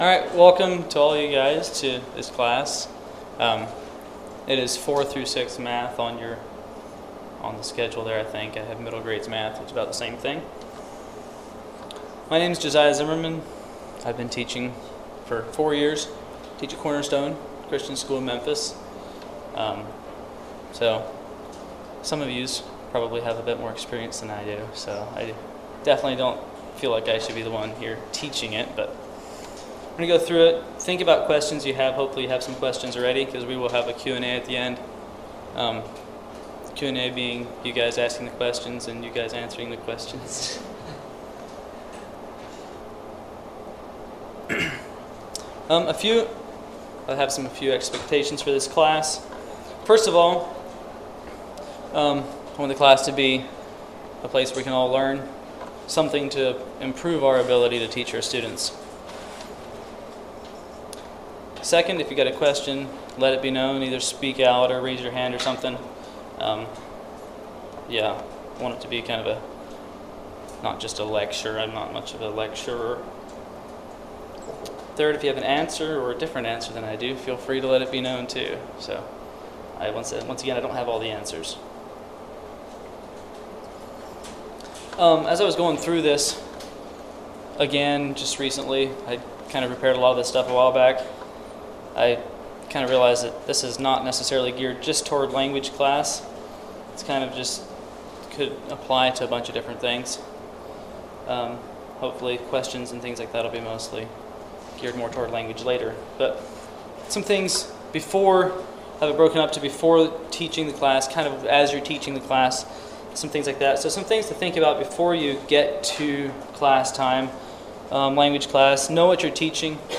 Home > Audio > 4-6 Lanuage 4-6 Lanuage Unknown Contributor August 28, 2025 Midwest Teachers Week 2025 Recordings 4-6 Lanuage Audio 00:00 / 01:04 Download We will be talking about some tips for teaching a typical language lesson.